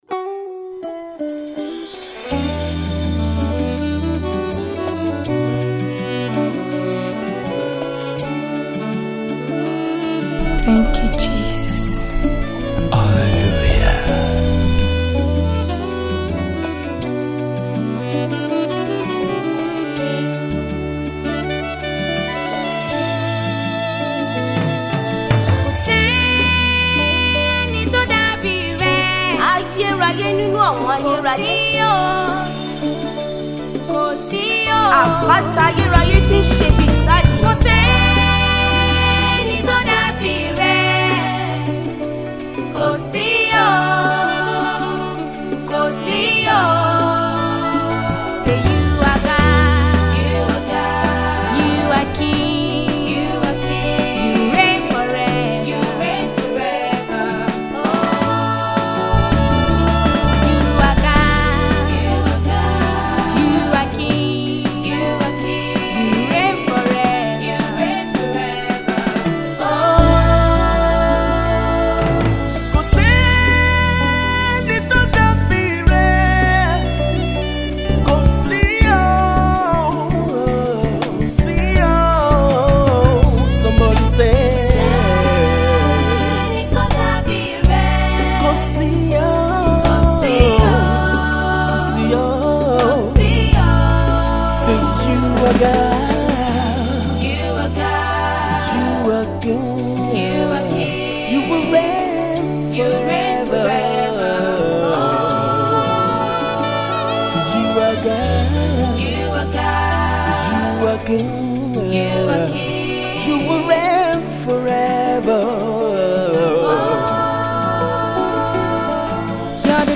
powerful Tune